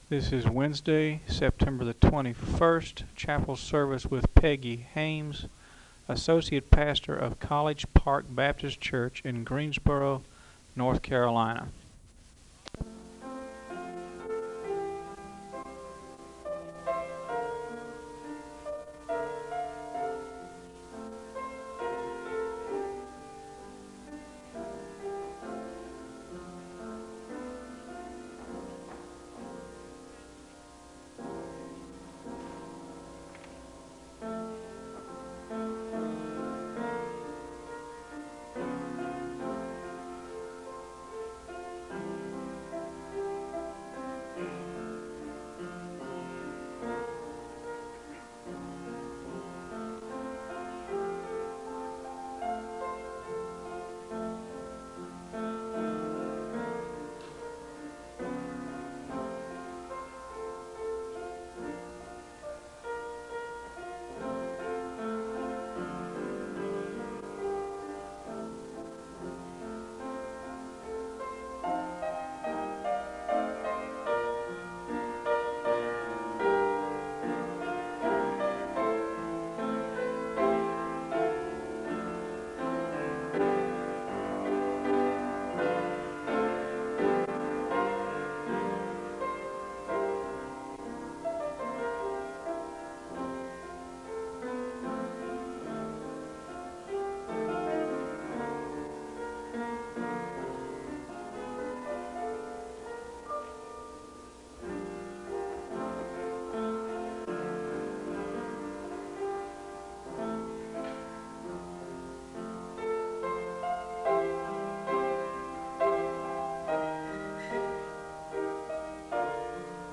An opening hymn is played (0:13-3:40). The congregation sings a song of worship (cut) (3:41-4:09). Prayer requests and a word of prayer are given (4:10-8:25).
The choir sings an anthem (9:21-11:46).